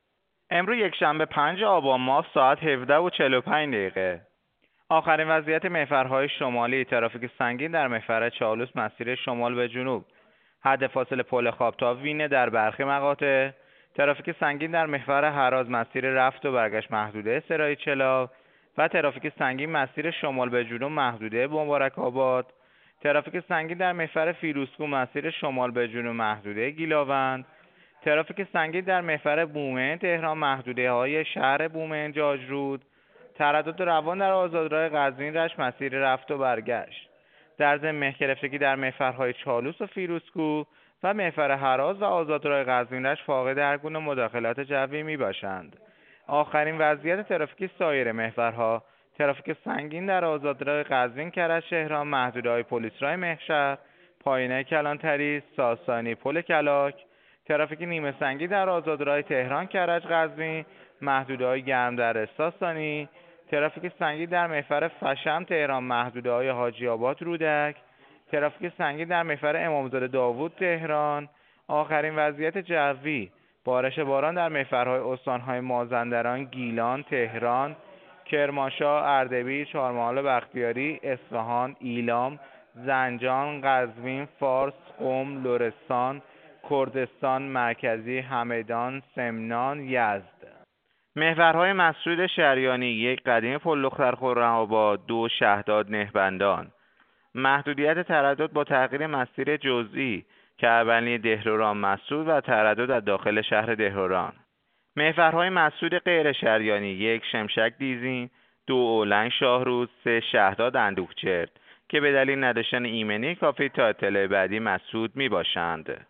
گزارش رادیو اینترنتی پایگاه خبری وزارت راه و شهرسازی از آخرین وضعیت ترافیکی جاده‌های کشور تا ساعت ۱۷:۴۵ پنجم آبان/ ترافیک سنگین در محورهای هراز، چالوس، فیروزکوه، بومهن-تهران، قزوین-کرج-تهران، فشم-تهران و امام زاده داوود-تهران